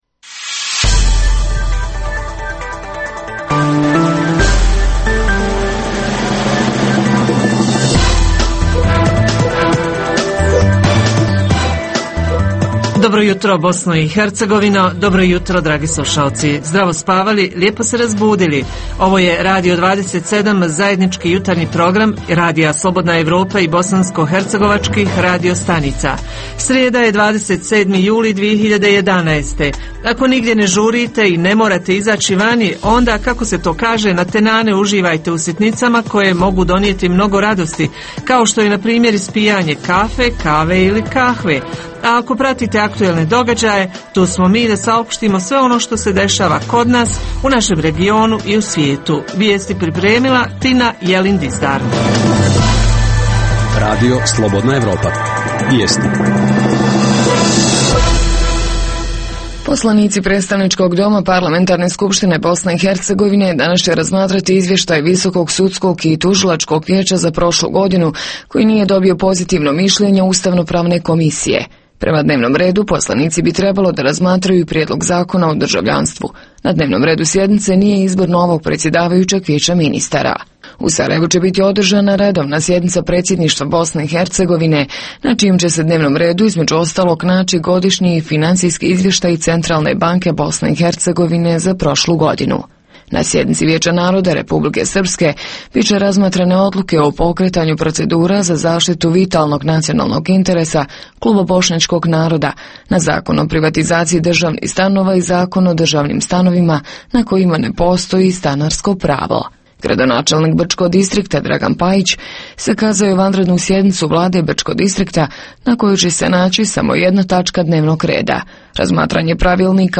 Jutarnji program za BiH koji se emituje uživo. Ovog jutra pitamo: gdje se osvježavaju naši sugrađani - u bazenima, rijekama, na moru?